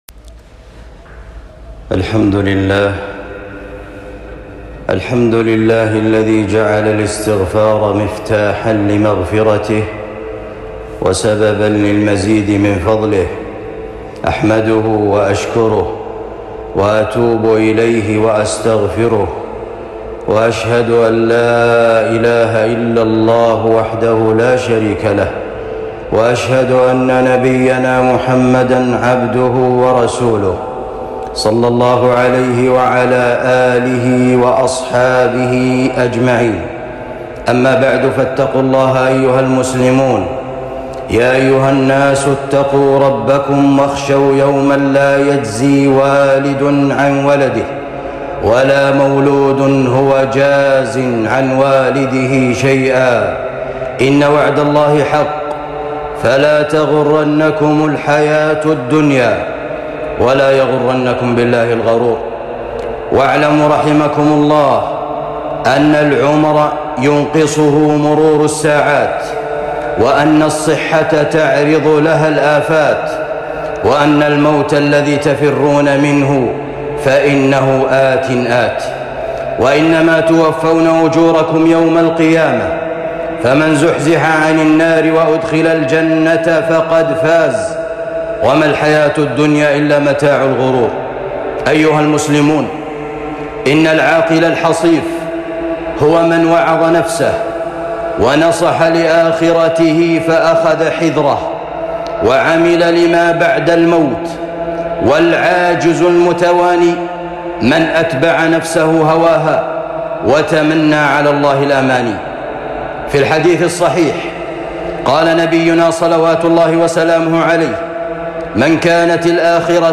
خطبة بعنوان (لمَ لا نتوب يا عباد الله؟)